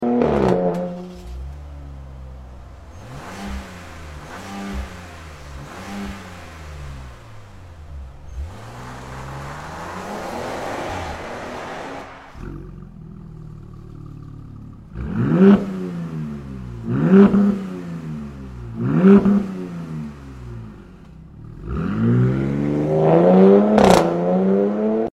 🔊 Now playing: AWE's 0FG Exhaust for the '25+ Dodge RAM 3.0TT